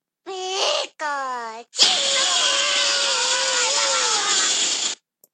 Download pikachuaaaa Sound effect Button free on Sound Buttons